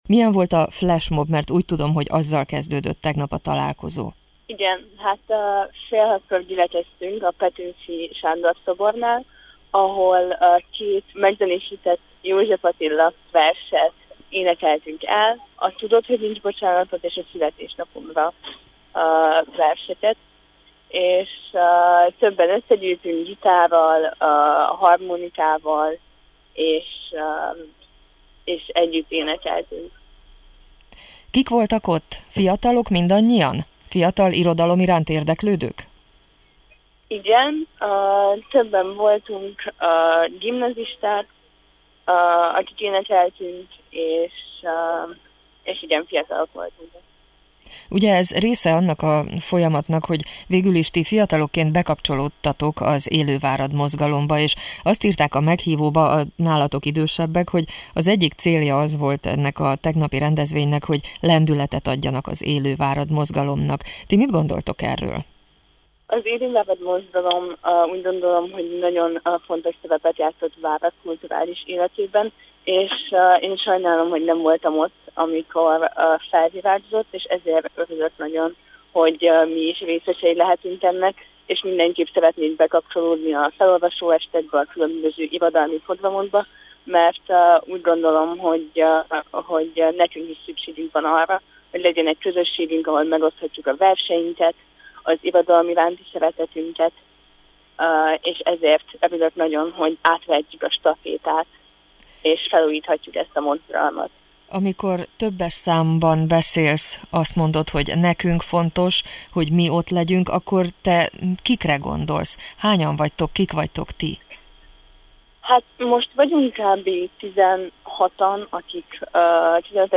A szervezők szerint, a rendezvény célja volt az is, hogy lendületet adjanak az Élő Várad Mozgalomnak egy új alkotói nemzedék színrelépésével. Két képviselőjüket faggattuk a Hangolóban